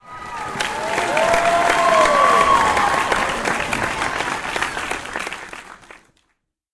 win.ogg